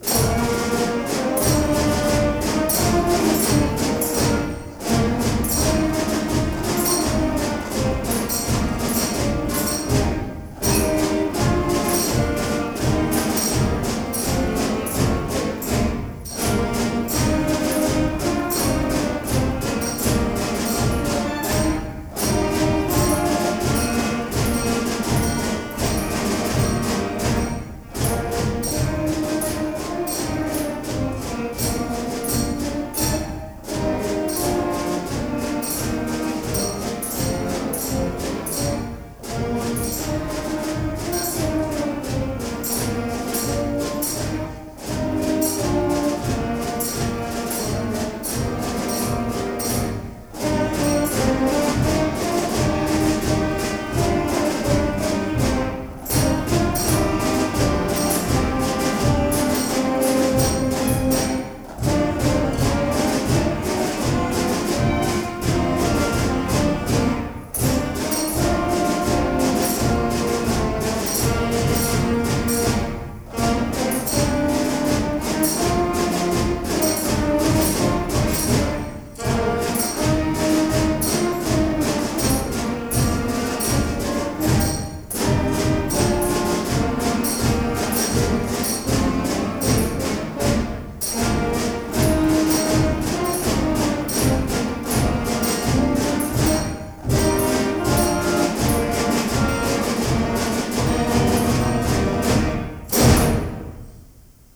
6-8 Grade Jr. High Band -